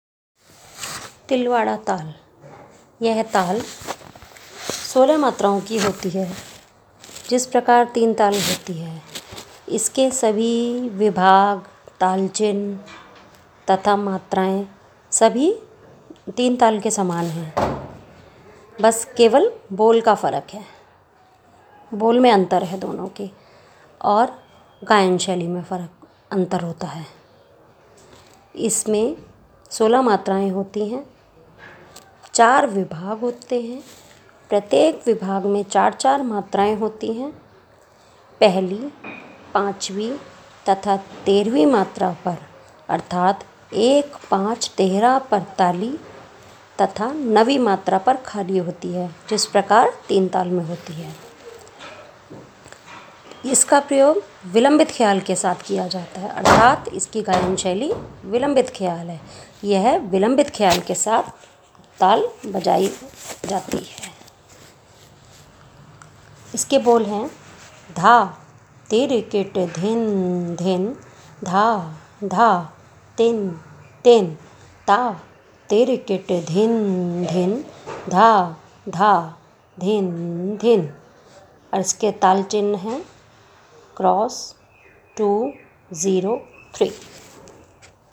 Tilwada Taal - theka Dugun chaugun. Audio